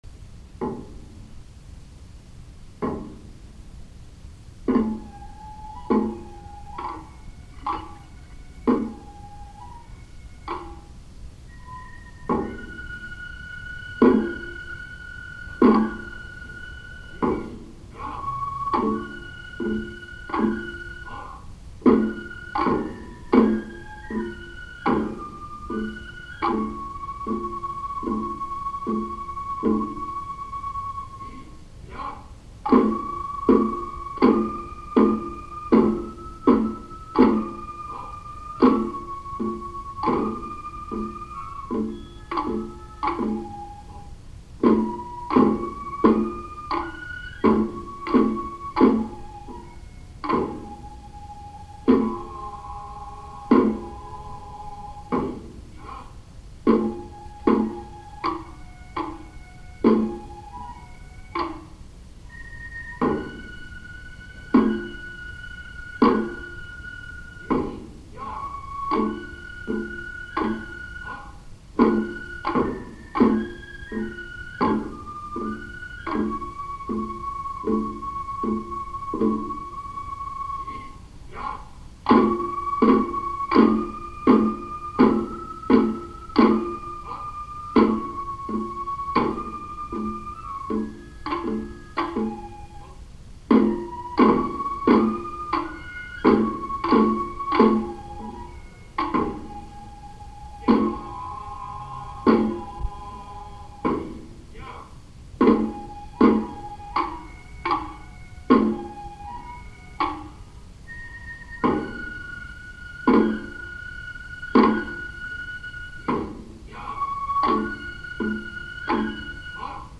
大太鼓，小太鼓，鼓，篠笛で演奏されます。
山車が曳かれる時に演奏されます。ゆっくりした比較的演奏し易い囃子です。